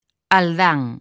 Transcrición fonética
alˈdaŋ